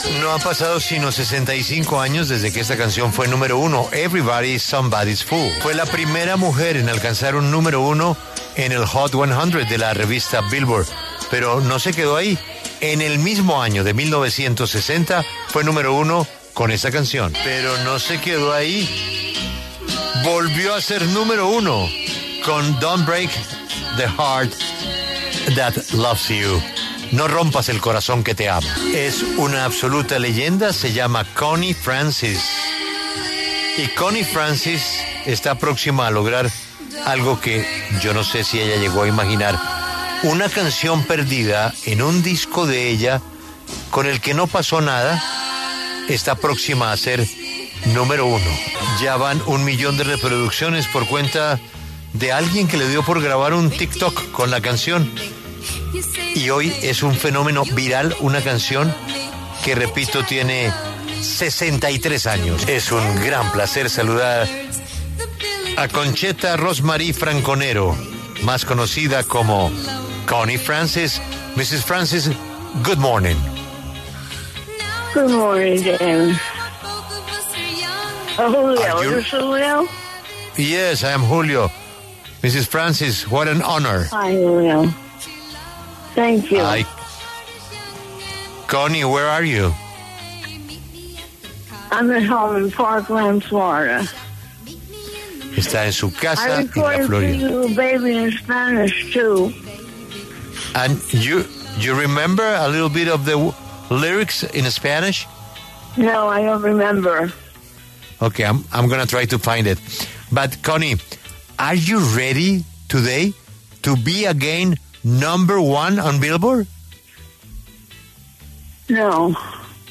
La cantante ítalo-estadounidense charló con La W sobre el inesperado éxito de su canción después de 63 años.
Para hablar sobre su inesperado éxito, la cantante Connie Francis pasó por los micrófonos de La W con Julio Sánchez Cristo y compartió diversos recuerdos de su carrera musical.